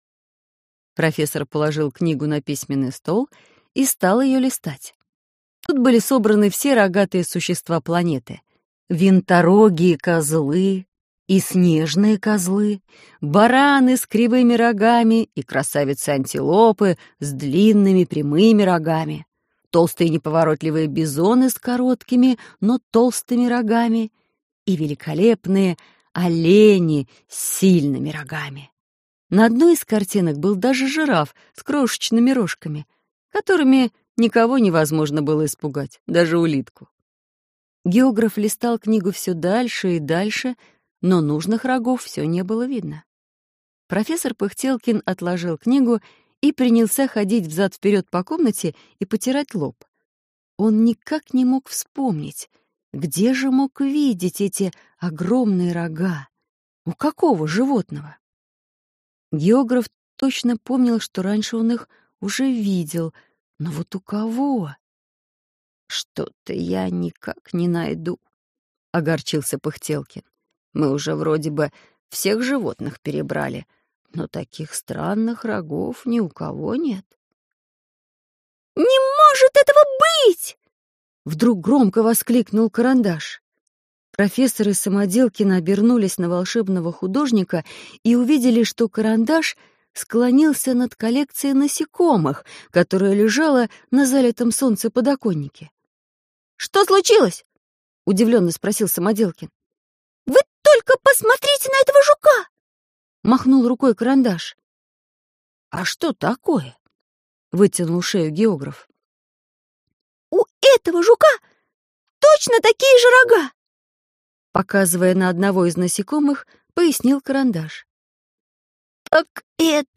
Аудиокнига Карандаш и Самоделкин на острове гигантских насекомых | Библиотека аудиокниг